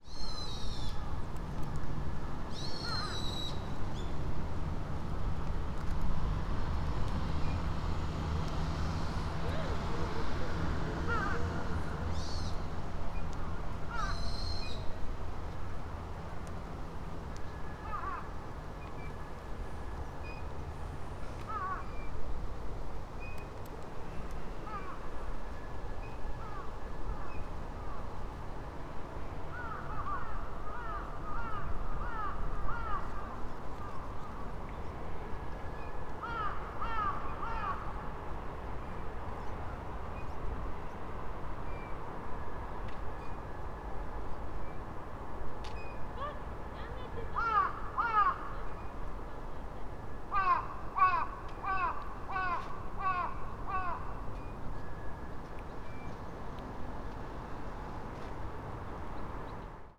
Fukushima Soundscape: Mt. Shinobu